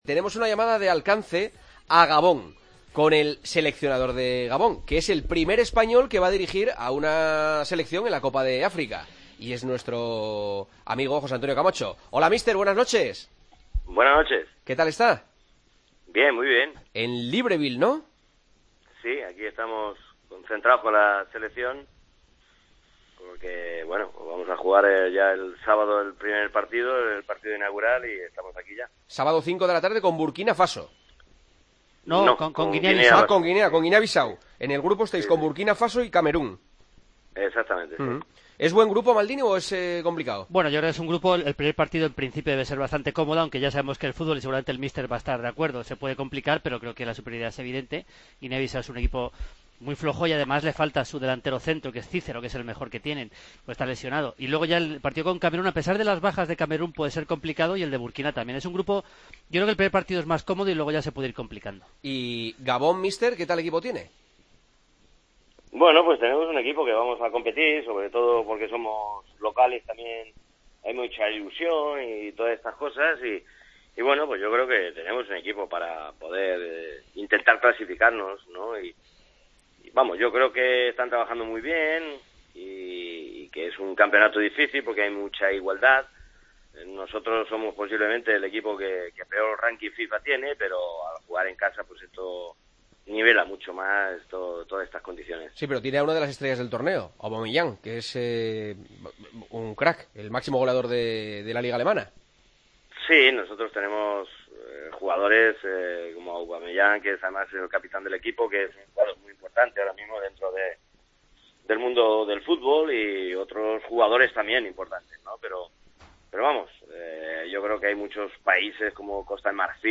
AUDIO: Charlamos con José Antonio Camacho, que afronta en unos días la Copa de África con la selección de Gabón:...